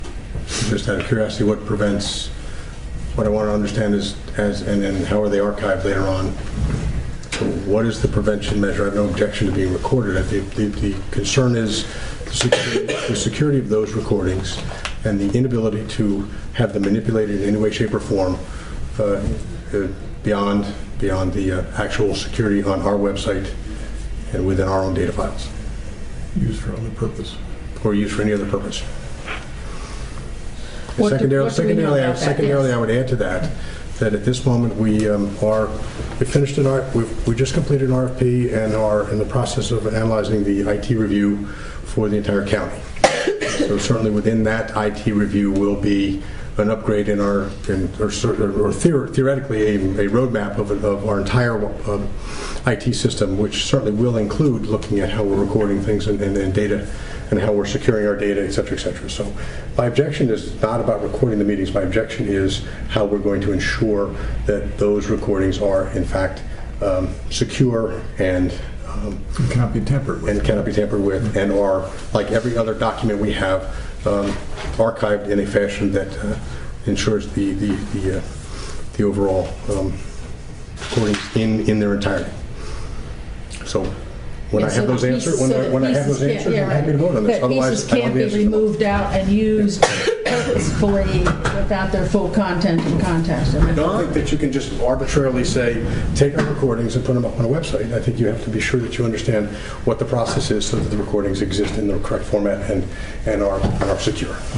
The following audio excerpts are from the December 20, 2016 meeting.
Holt nervously stumbled and bumbled through an almost incomprehensible explanation about the “security” of the recordings.